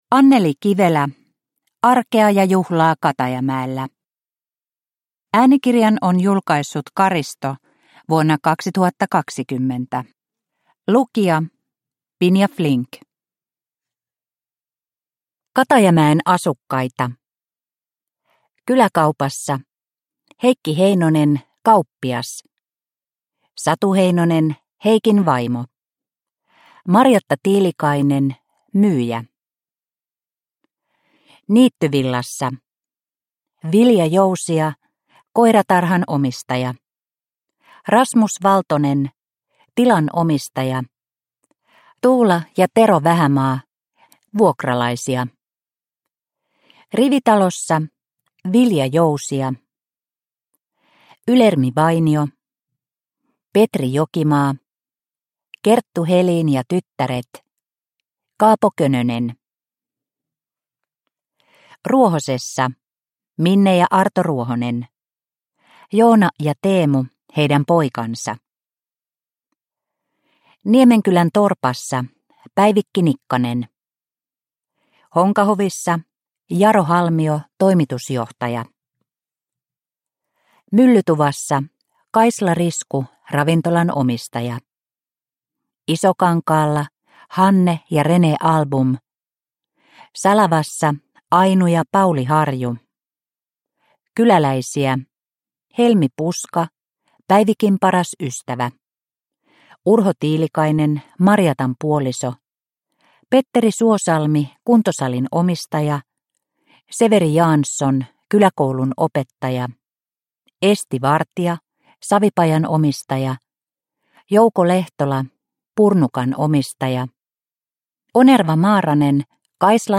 Arkea ja juhlaa Katajamäellä – Ljudbok – Laddas ner
Produkttyp: Digitala böcker